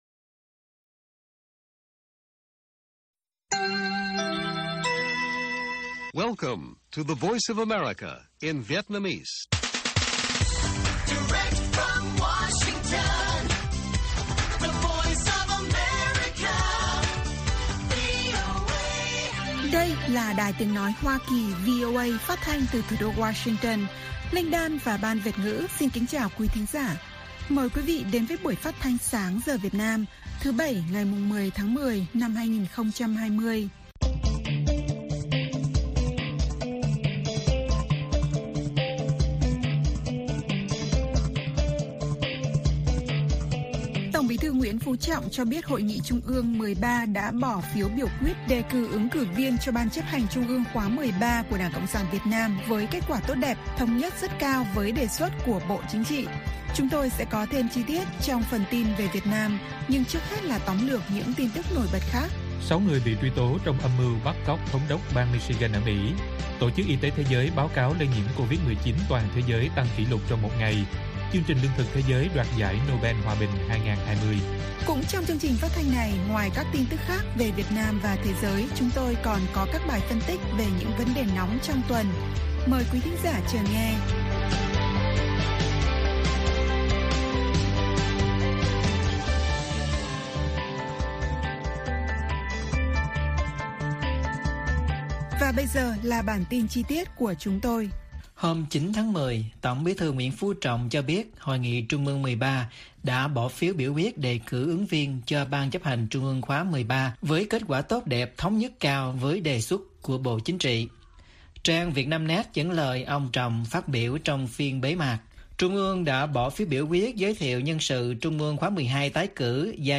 Bản tin VOA ngày 10/10/2020